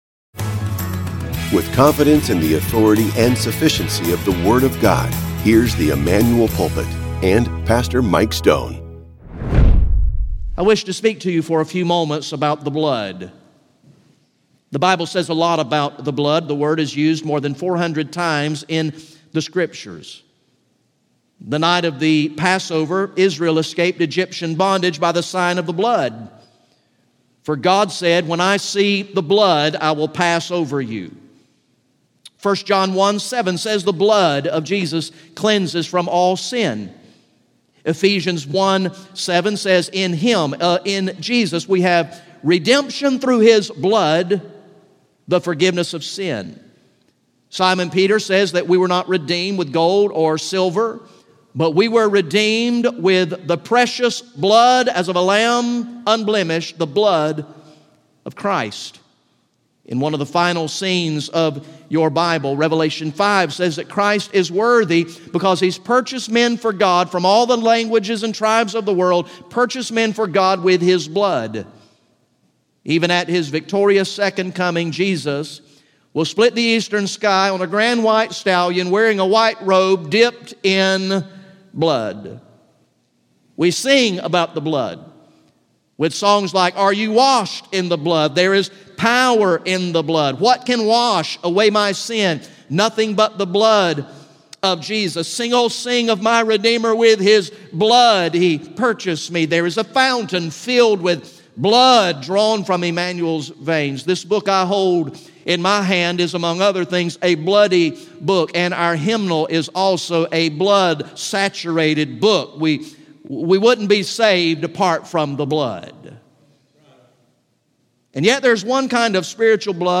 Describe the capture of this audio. GA Sunday PM